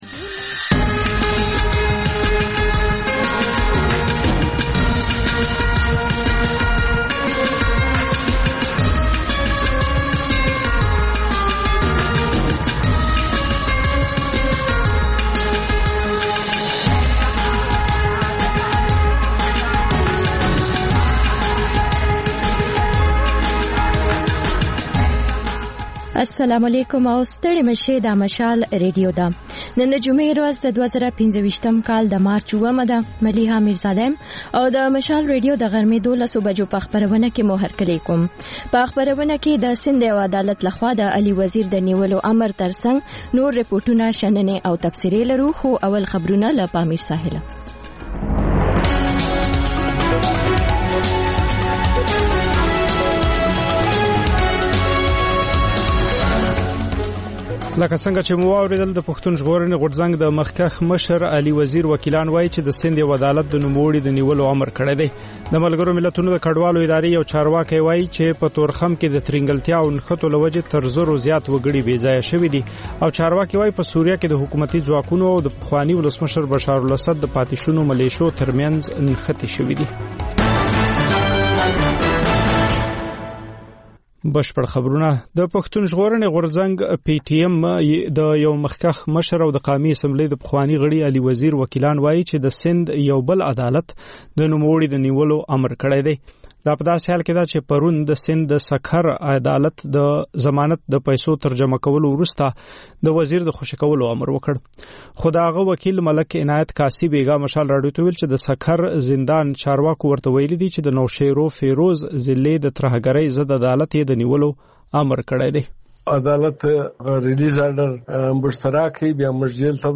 د مشال راډیو د ۱۴ ساعته خپرونو په لومړۍ خبري ګړۍ کې تازه خبرونه، رپورټونه، شننې، مرکې او کلتوري، فرهنګي رپورټونه خپرېږي.